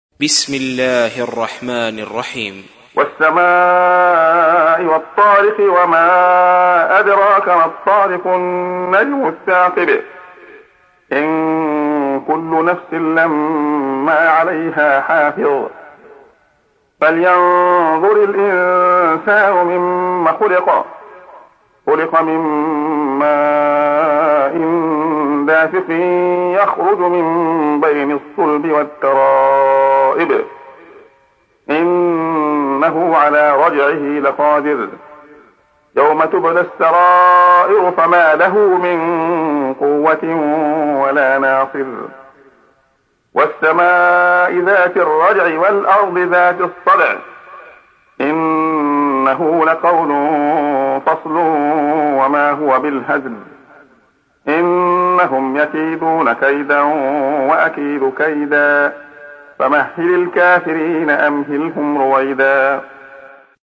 تحميل : 86. سورة الطارق / القارئ عبد الله خياط / القرآن الكريم / موقع يا حسين